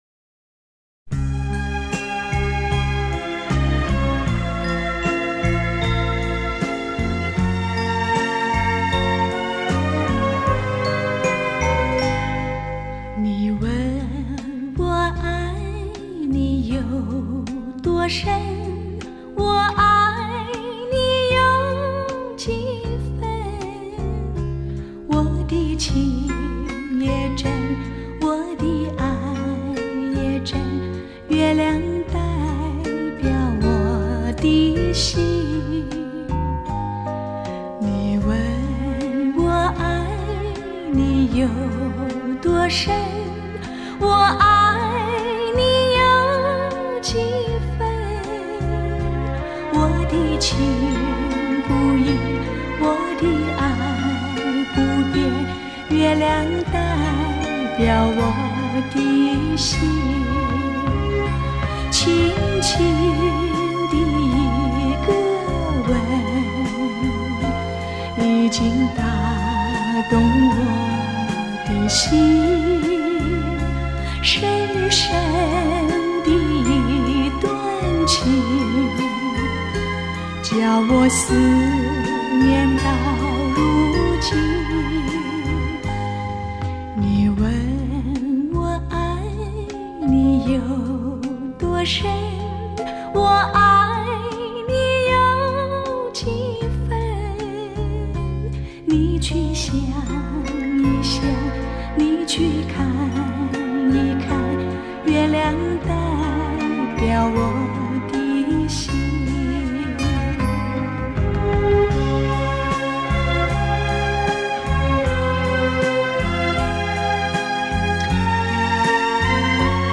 Popular Chinese Song